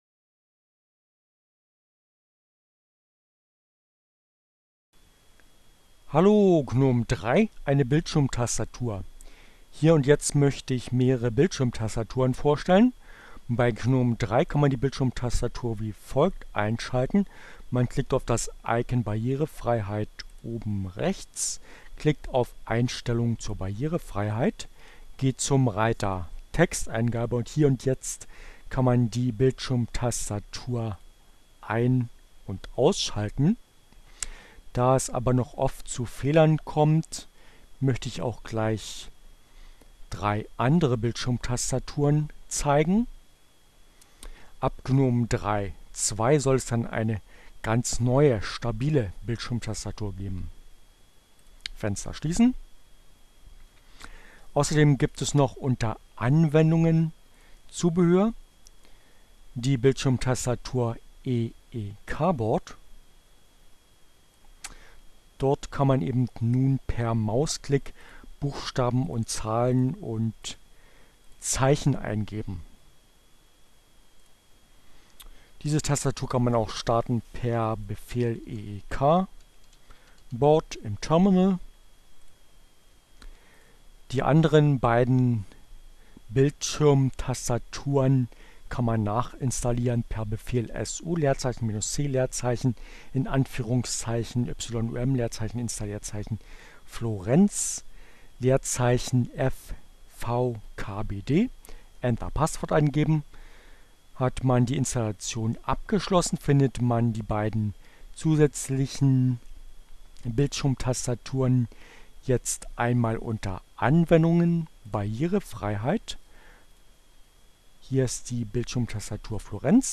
Tags: CC by-sa, Fedora, Gnome, Linux, Neueinsteiger, Ogg Theora, ohne Musik, screencast, gnome3, Bildschirmtastatur